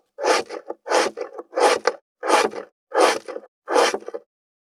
519切る,包丁,厨房,台所,野菜切る,咀嚼音,ナイフ,調理音,まな板の上,料理,